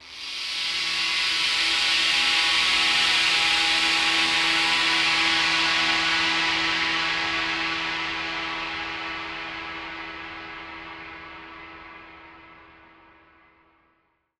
SaS_HiFilterPad05-E.wav